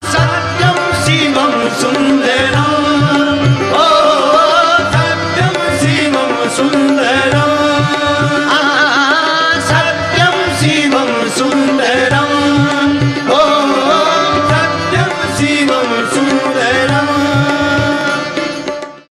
индийские , live